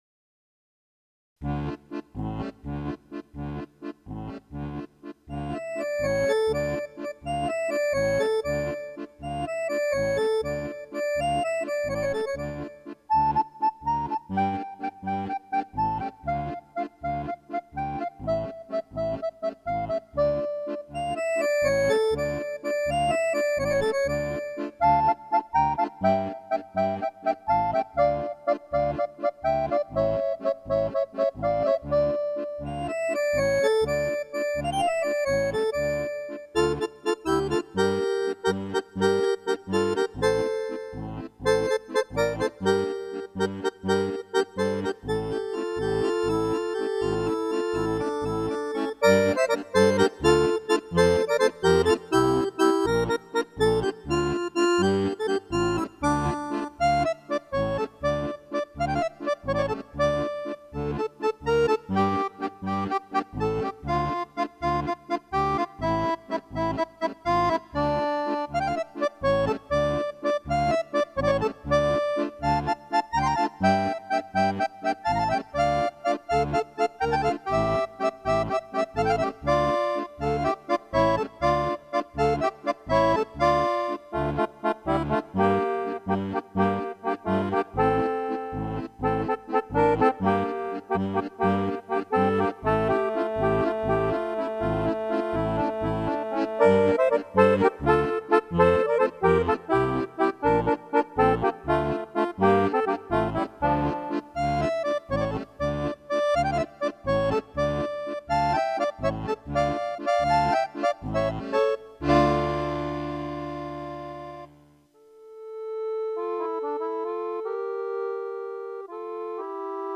cooler Sound
Mit reduzierter Emotion Drauf gekommen bin ich durch Oldie-Schwelgerei mit WilliamBasie.